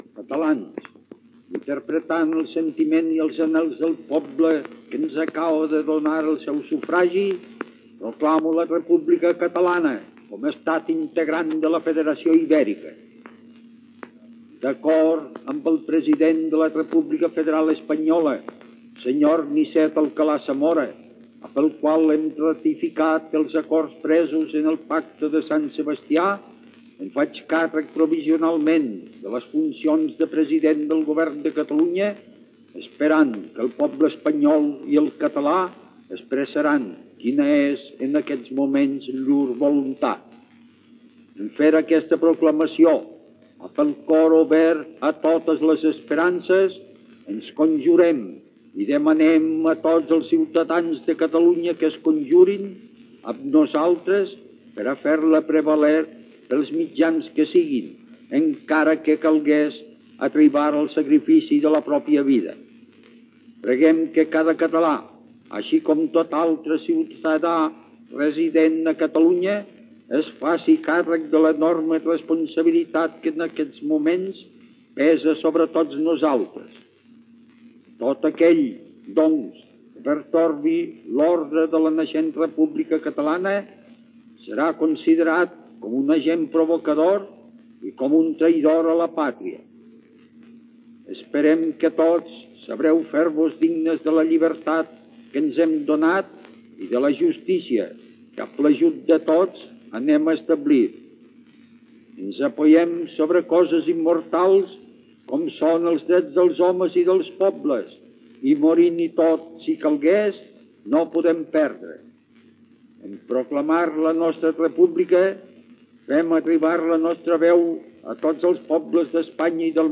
Discurs de Francesc Macià proclamant la República Catalana.
El que escoltem no és la gravació directa de l'al·locució radiofònica, sinó una recreació posterior.